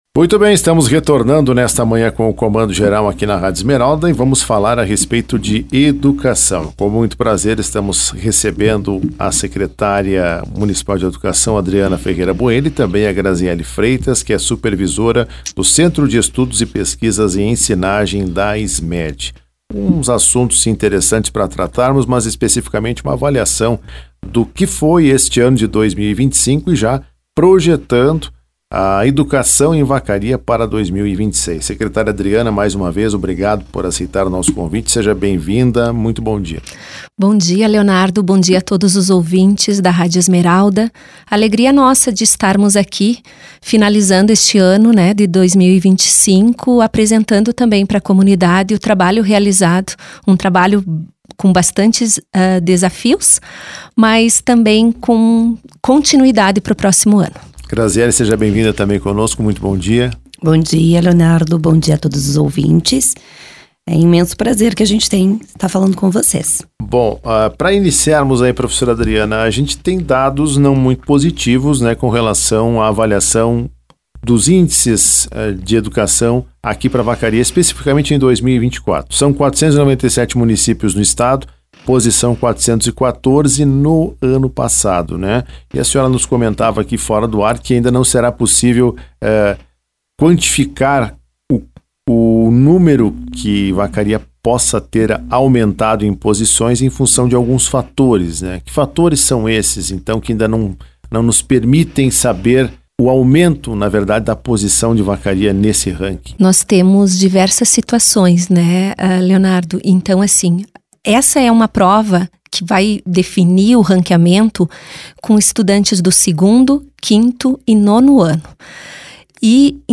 Comando Geral. Durante a entrevista elas fizeram uma avaliação das atividades realizadas ao longo de 2025 pela SMED.